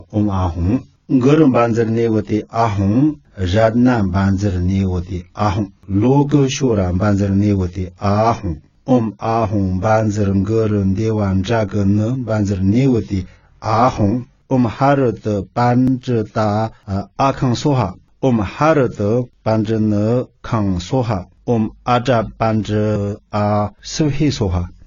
b03-佛法融入生活-饭前供施咒.mp3